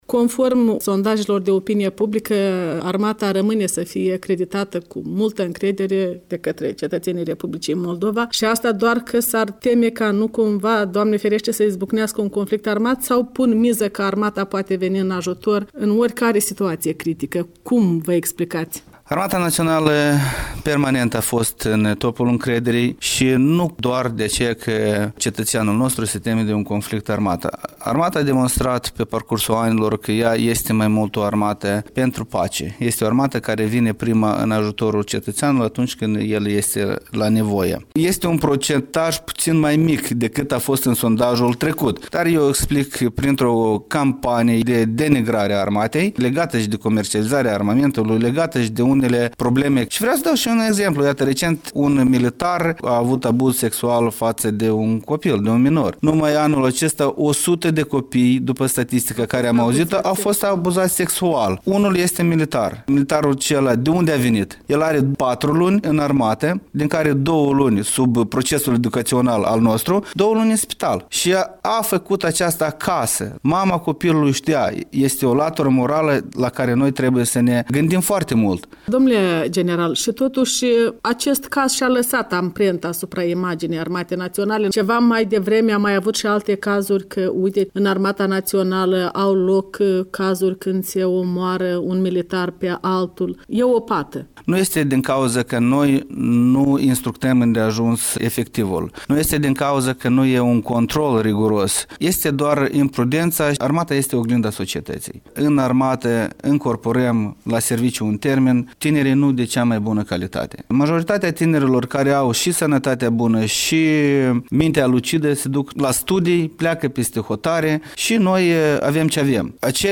Un interviu cu Vitalie Marinuță